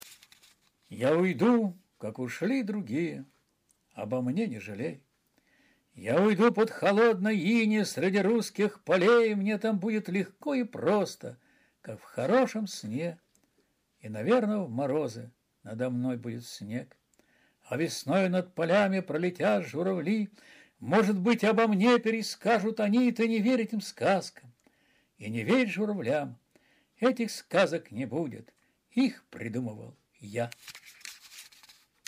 Стихи